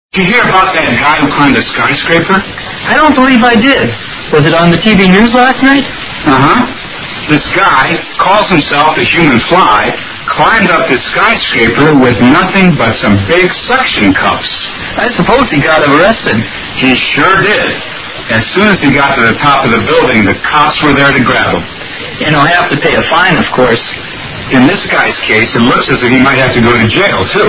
英语对话听力mp3下载Listen 30:THE HUMAN FLY